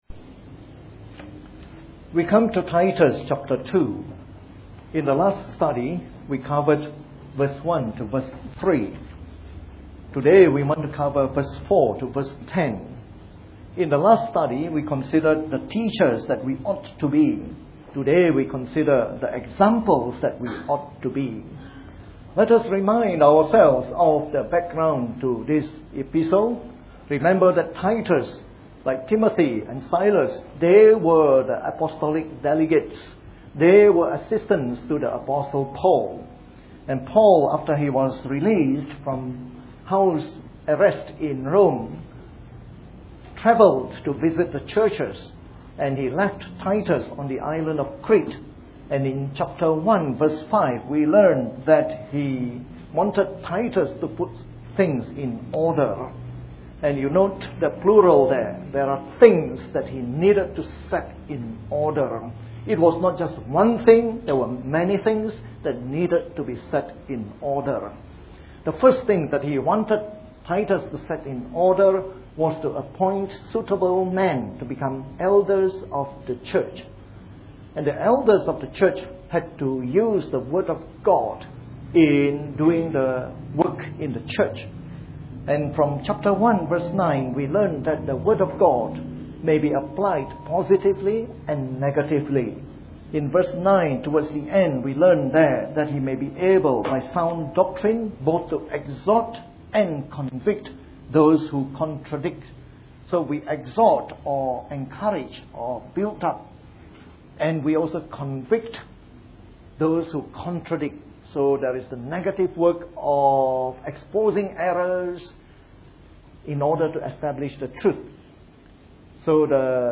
A sermon in the morning service from our series on Titus.